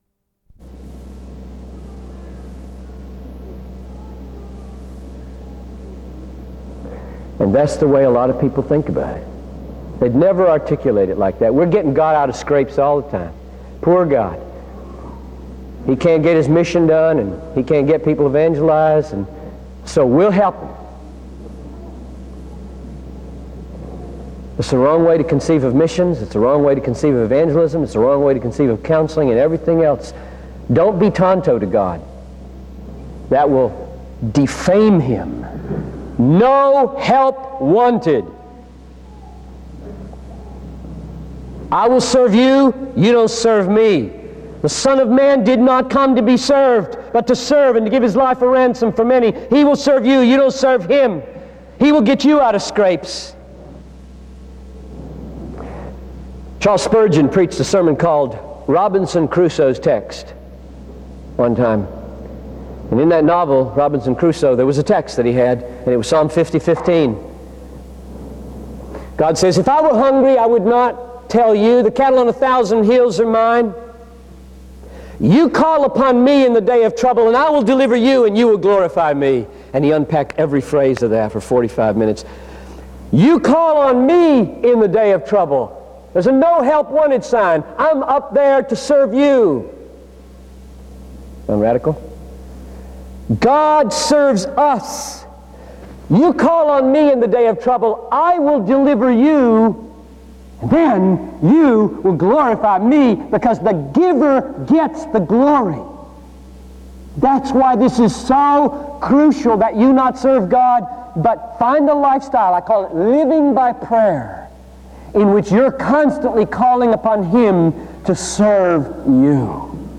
SEBTS Carver-Barnes Lecture - John Piper September 27, 2000
SEBTS Chapel and Special Event Recordings